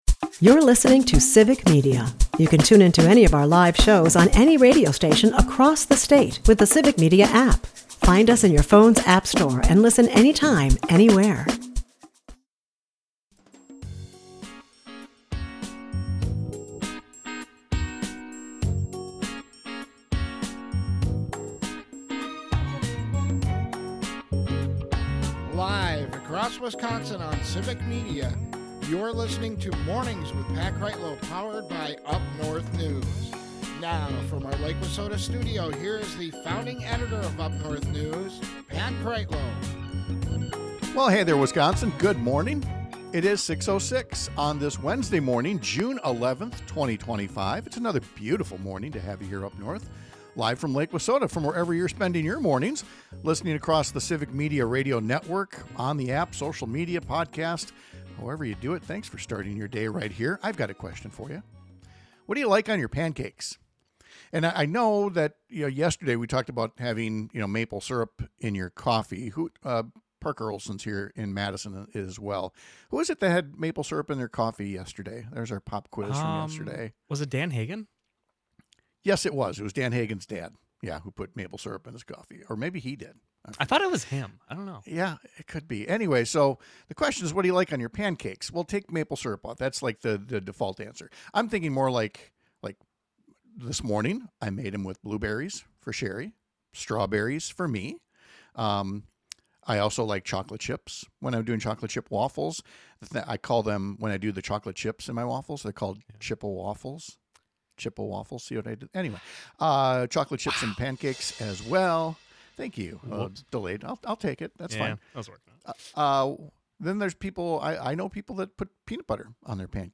In fact, we need to correct our language from yesterday on just how much will be cut from the stable, affordable healthcare coverage that millions of working families rely on for security. We’ll talk to a Madison area chef about why small business owners want the Legislature to stop its attempt to kill a working program that makes childcare more affordable.